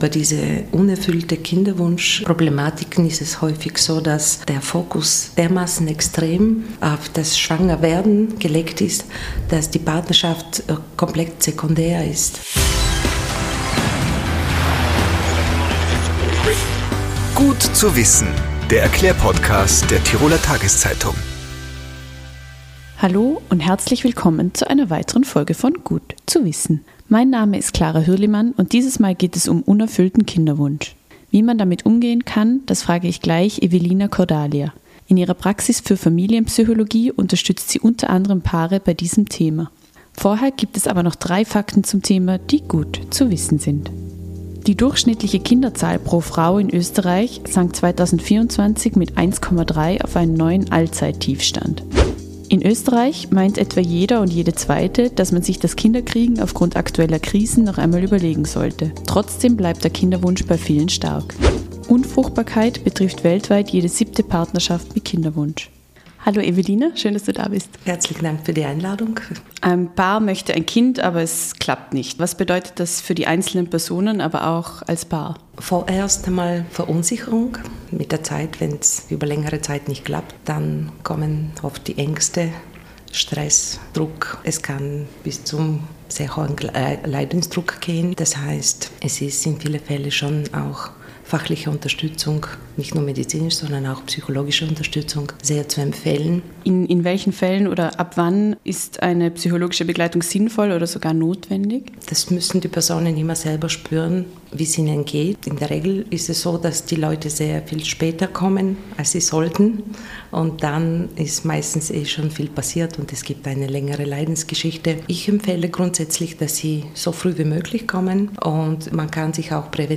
Sexualtherapeutin im Gespräch Offene Beziehungen polarisieren die Gesellschaft: Kann so etwas funktionieren oder ist es nicht vielmehr der verzweifelte Versuch, eine gescheiterte Beziehung zu retten?